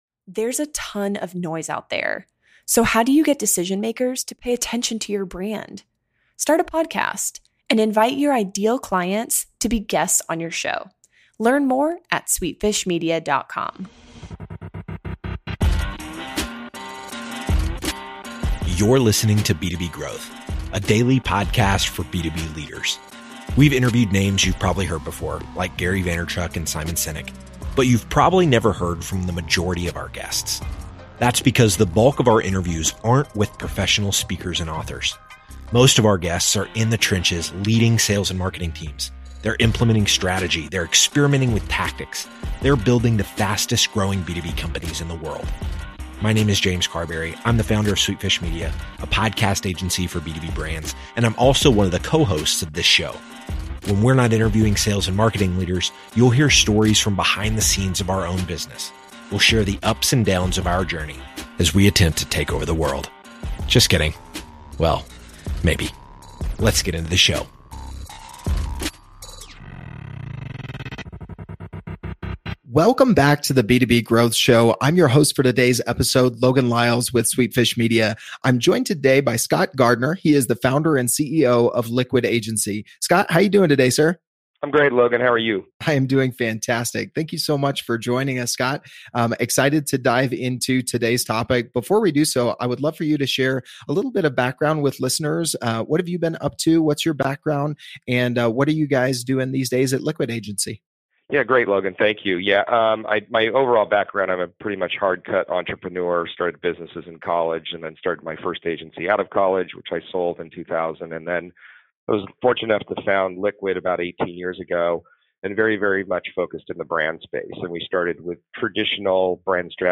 Each episode features an interview with a B2B marketing executive or thought leader.